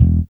4RB96BASS.wav